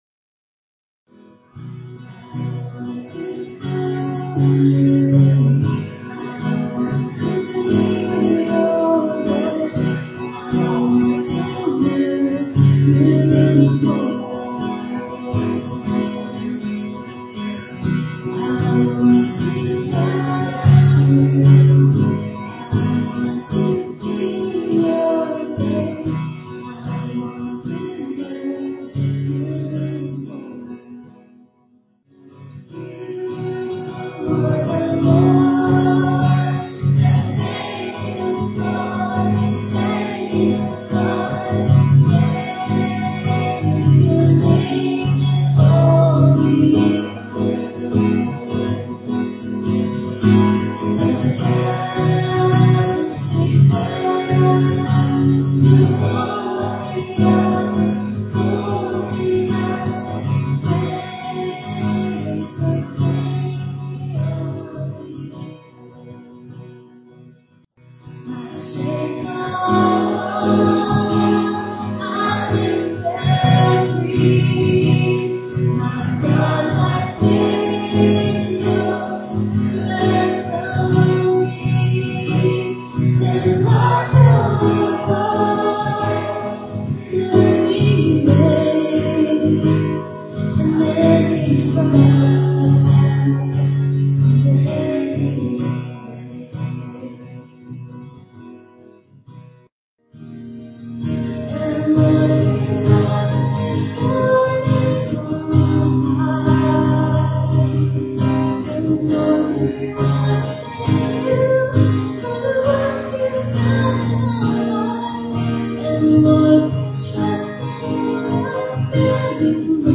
PLAY David: Heroic Faith (Part 5), June 12, 2011 Scripture: 1 Samuel 26:1-25. Message
at Ewa Beach Baptist Church.